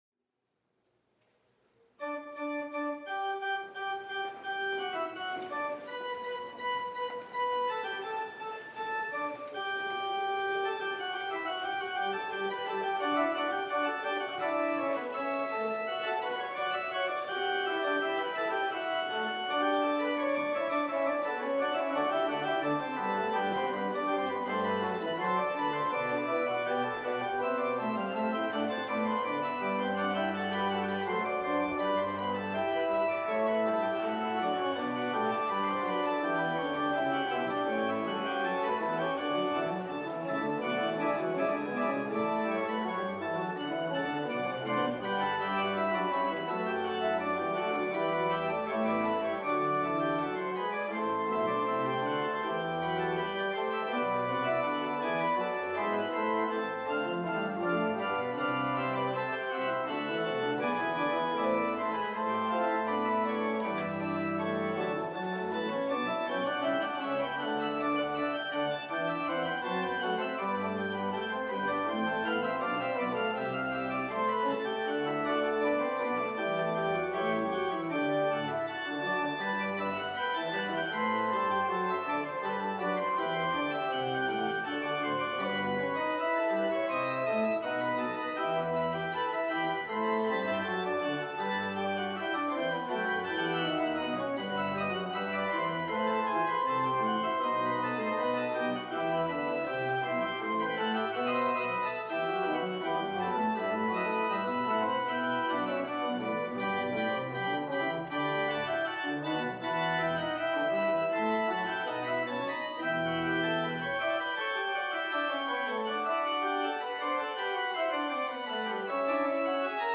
Central-Church-4-5-20-worship.mp3